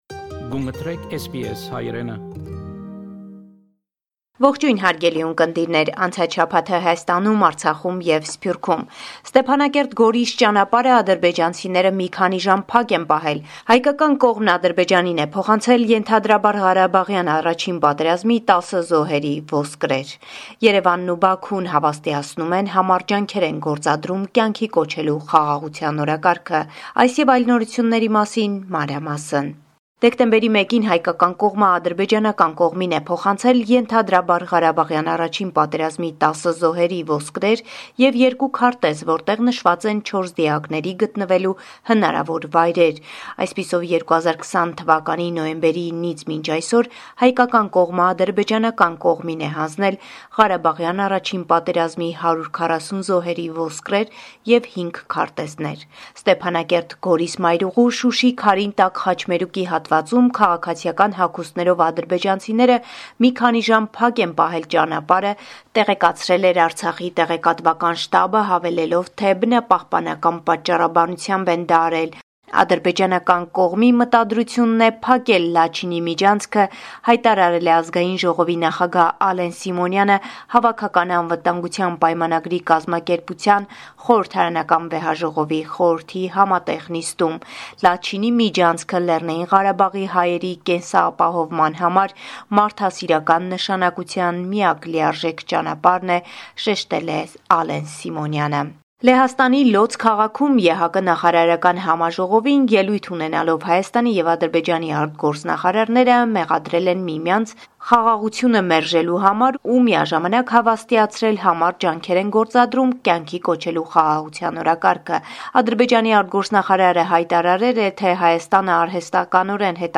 Latest News from Armenia – 6 December 2022